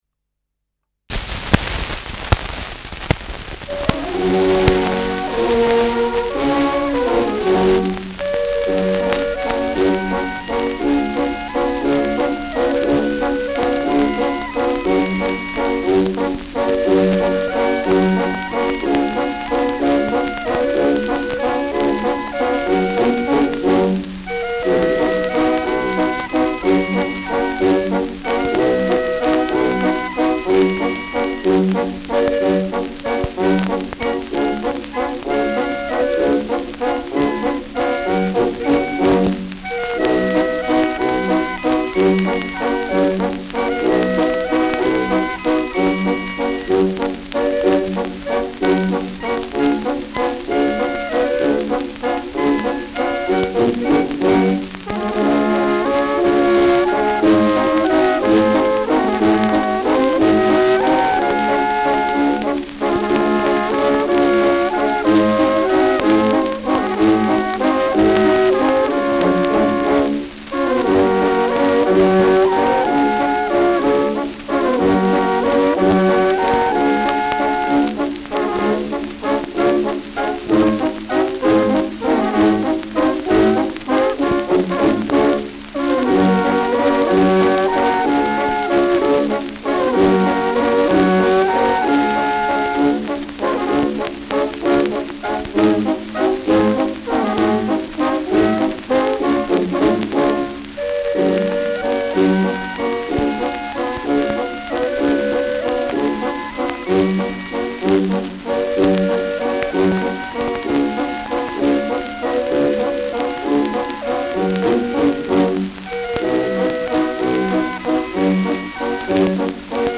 Schellackplatte
präsentes Rauschen : vereinzelt Knacken
Kapelle Jais (Interpretation)